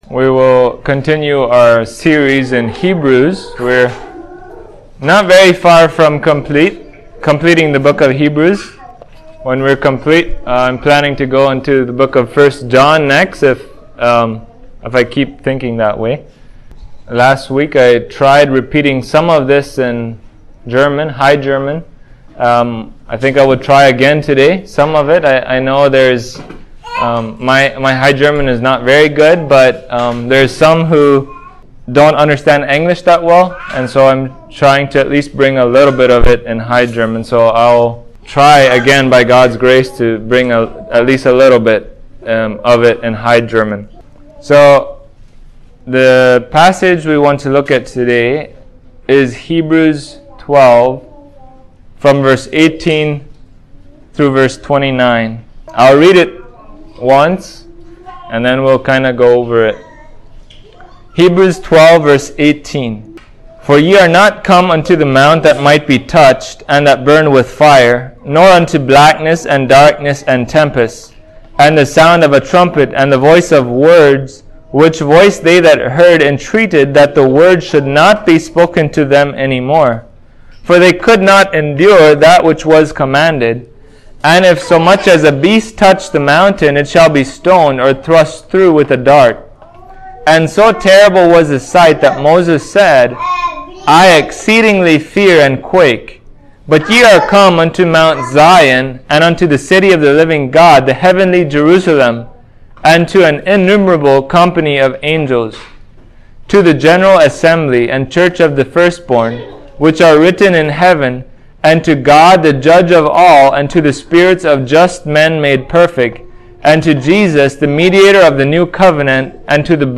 Passage: Hebrews 12:18-29 Service Type: Sunday Morning